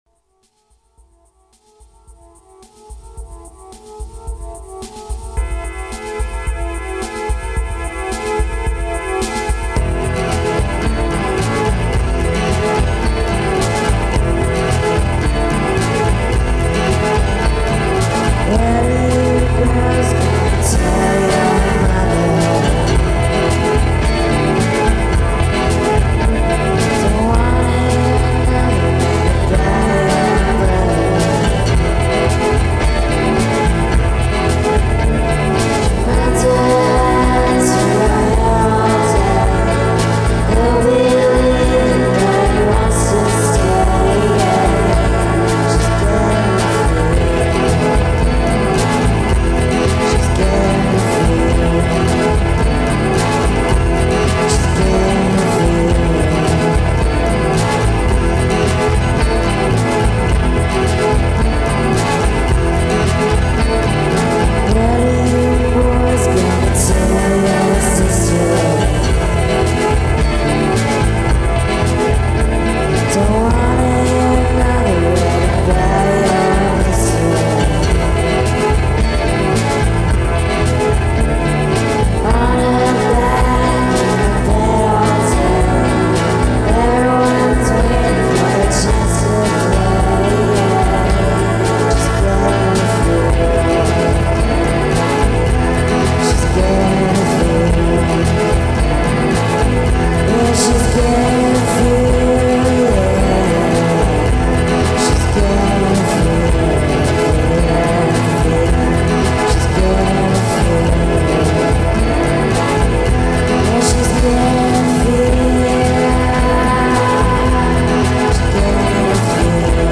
lo-fi Psych/alt/Canadian Rock